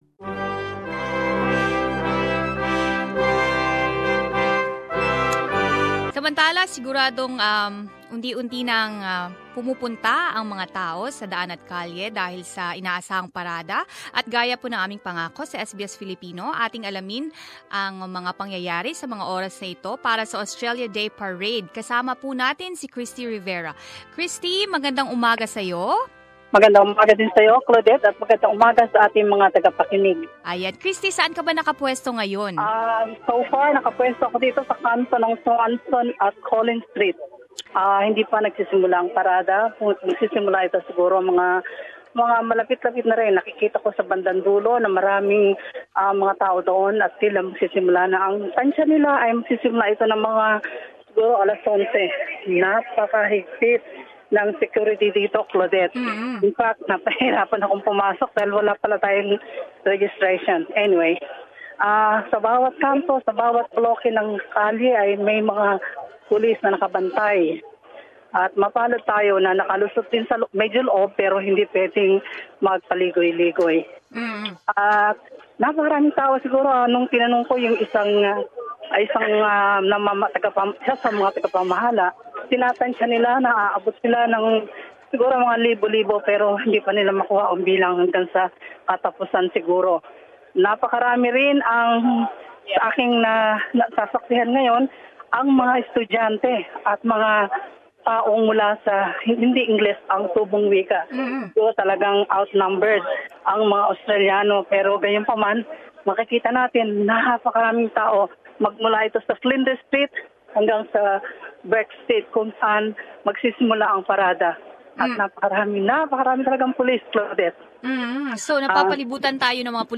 Multikultural na madla dumagsa para sa parada ng araw ng Australya sa Melbourne